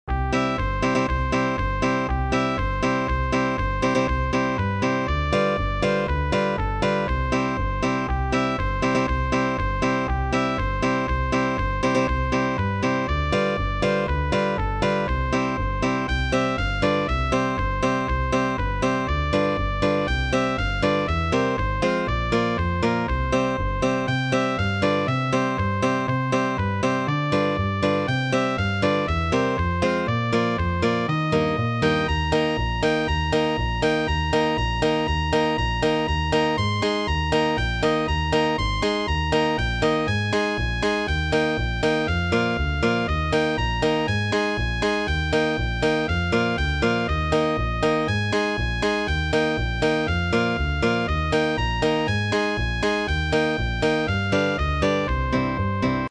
היה קטע דיסוננסי להחריד עם האקורדים..
חוץ מזה הקצב ממש מגניב!
אם לא שמת לב - עברתי סולם באמצע!
וממש בסוף חזרתי לסולם המקורי
=[[ אני ממש שונאת מוזיקה ממוחשבת..
מזכיר קצב של שיר ילדים..